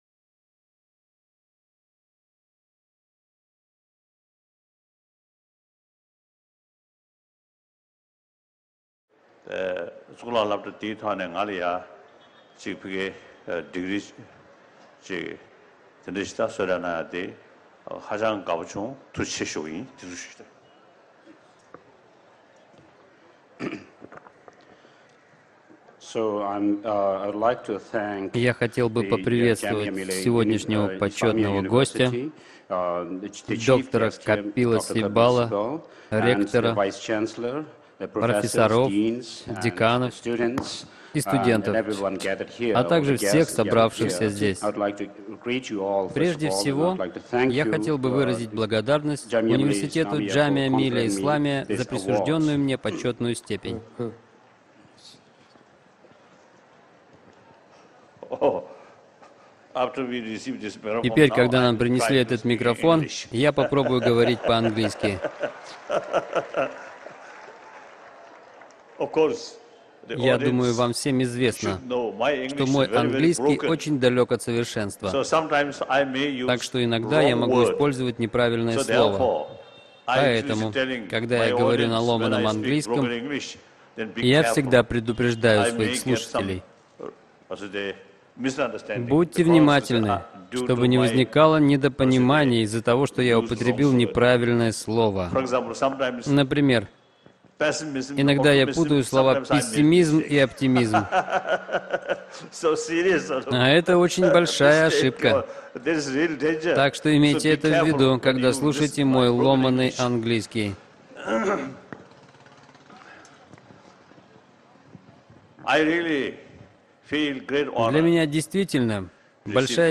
Аудиокнига Далай-лама в исламском университете | Библиотека аудиокниг